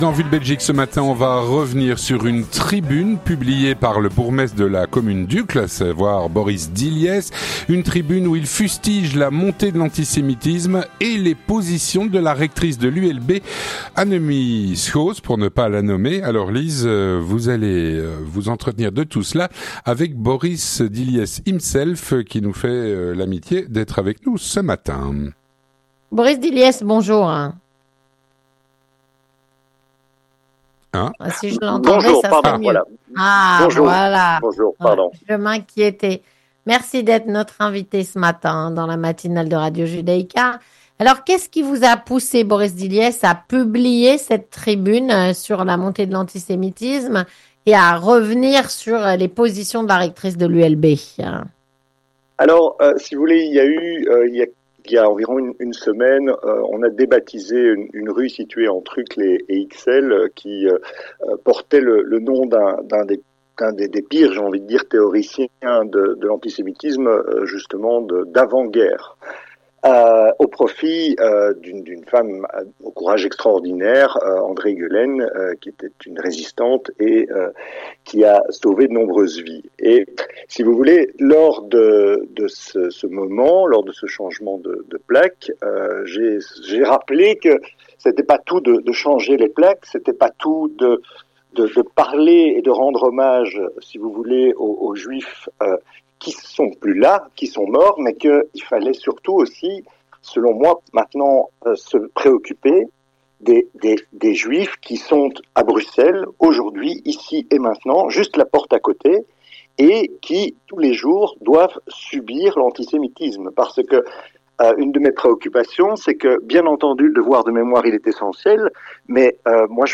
On en parle avec son auteur, Boris Dilliès, Bourgmestre de la commune d'Uccle.